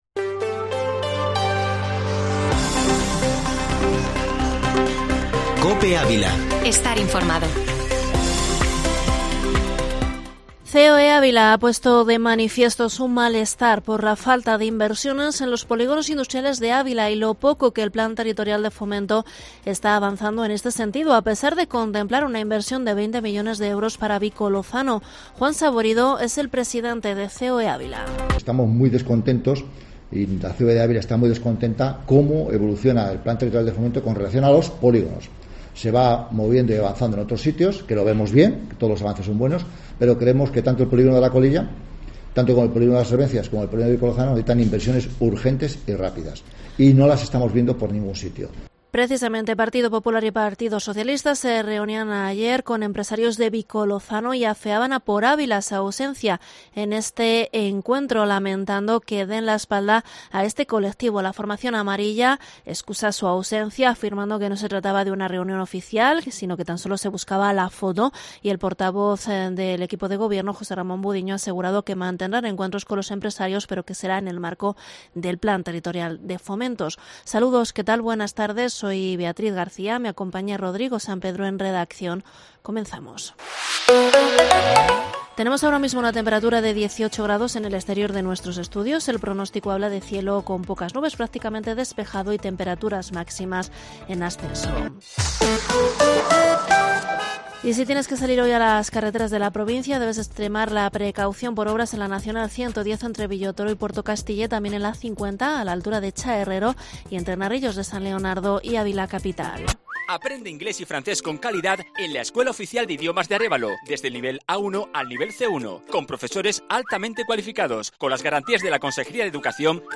informativo Mediodía COPE ÁVILA 07/10/2021